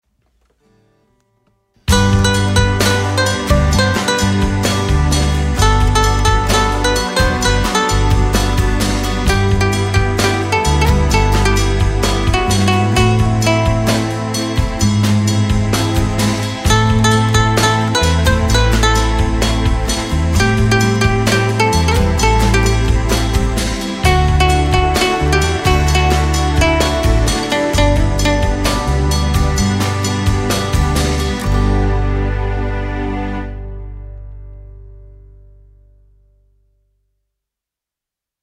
ringard - ancien - kitsch - romantique - marrant